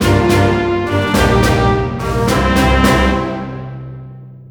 music are now 16bits mono instead of stereo
victory.wav